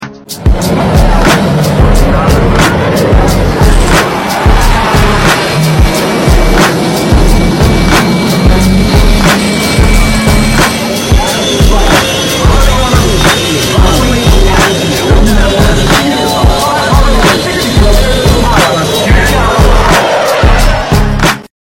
Left To Right Torukmakto Engines sound effects free download